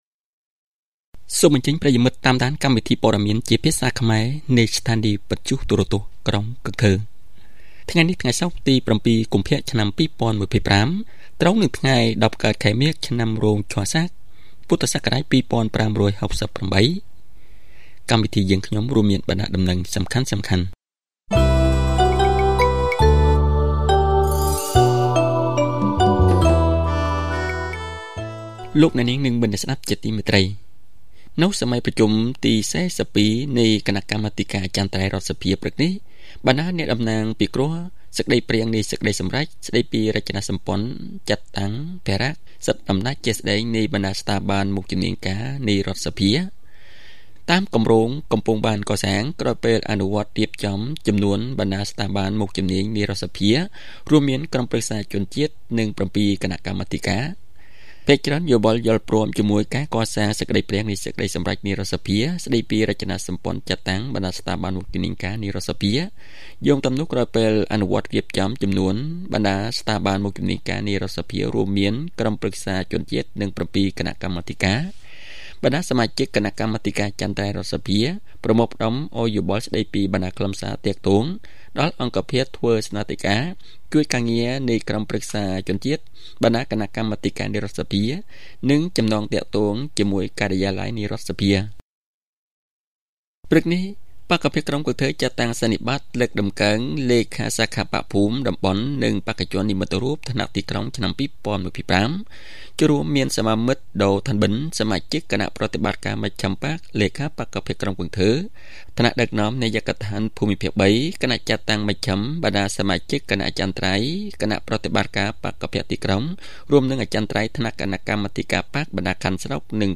Bản tin tiếng Khmer tối 7/2/2025